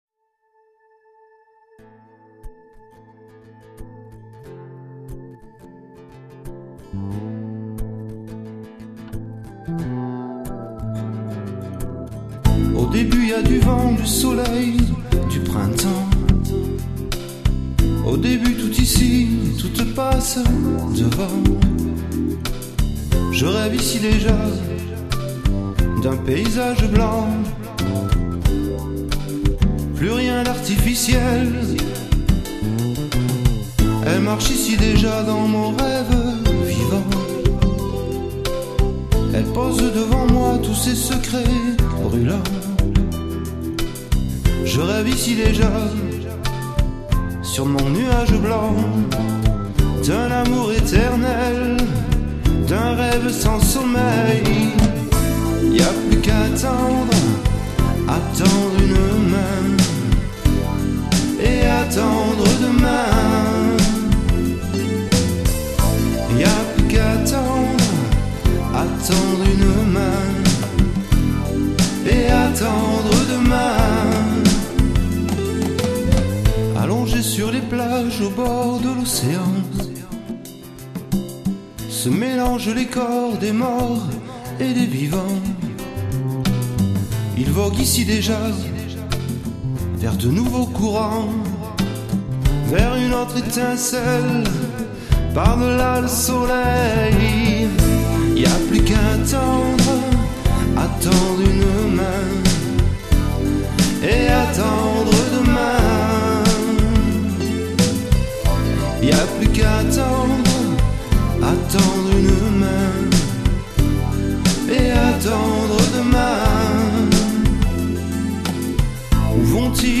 non le folk n'est pas mort!! extrait mp3 :